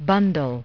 bundle – [ buhn-dl ] – / ˈbʌn dl /